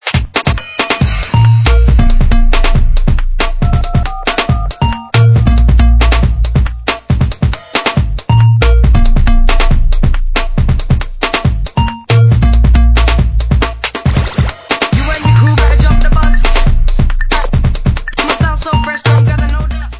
sledovat novinky v kategorii Dance
sledovat novinky v oddělení Dance/Techno